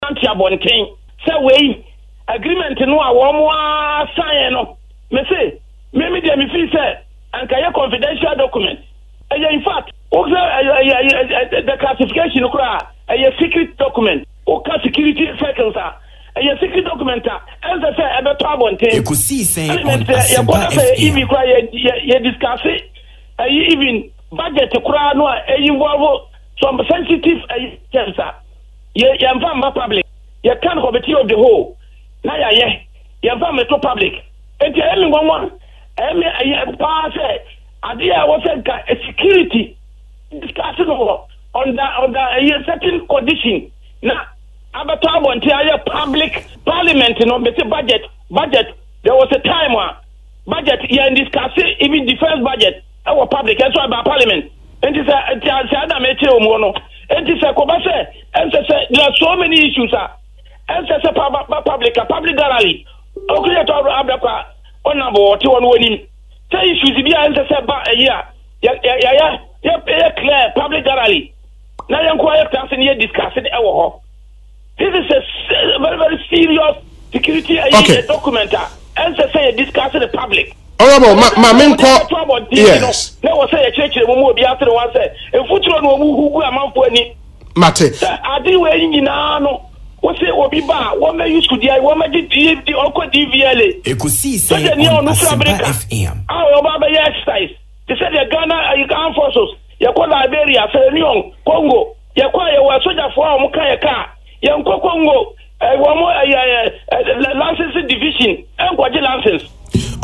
But Major (Rtd) Derek Oduro speaking on Asempa FM’s Ekosii Sen Tuesday noted that President Akufo Addo has competent people to deal with the issue and therefore it was needless to call on the President to speak on the issue.